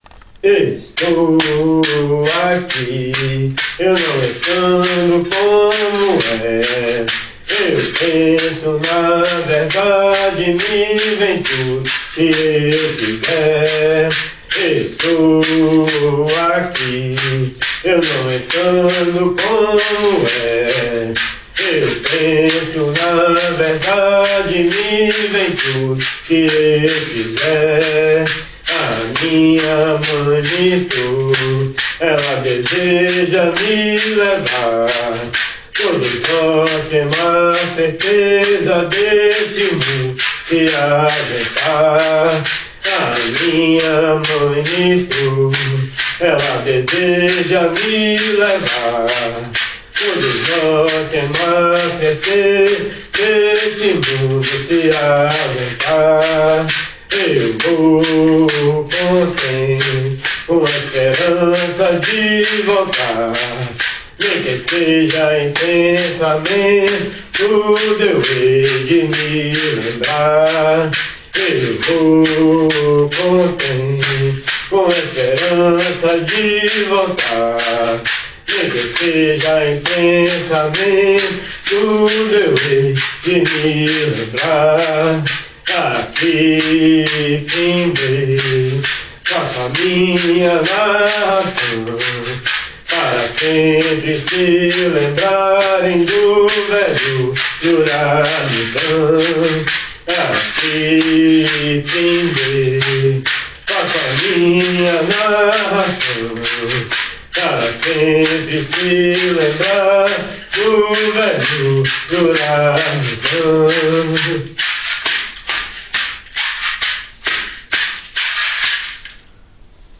Hymne 111 Estou Aqui von Hymnarium O Cruzeiro